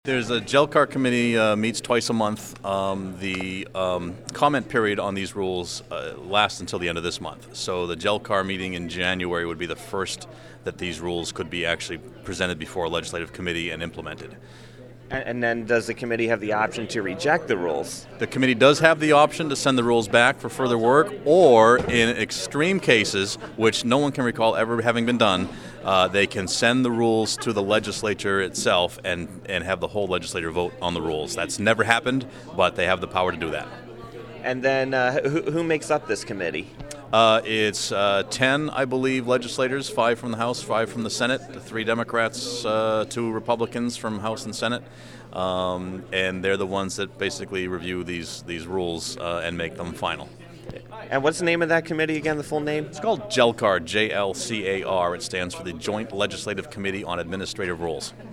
The following audio cuts are interviews I did 12/16/09 at the LLC Tax hearing in Concord and played on the show:
Interview- Sen. Peter Bragdon (R-Milford)